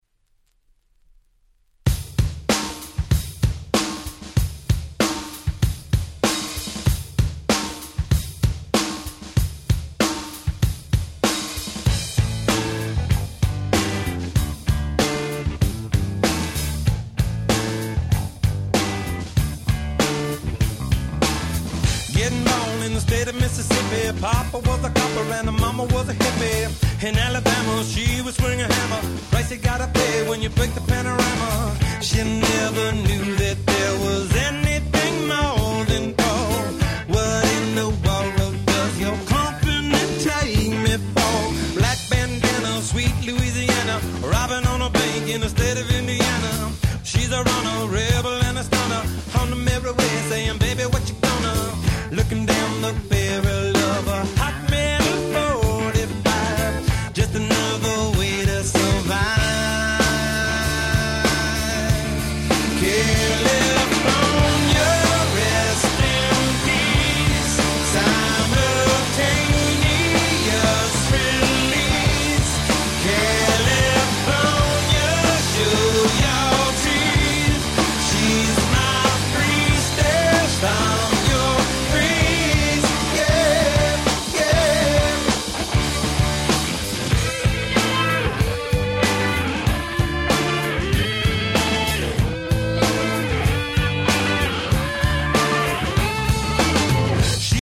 繋ぎやすくIntroも配備され至れり尽くせり！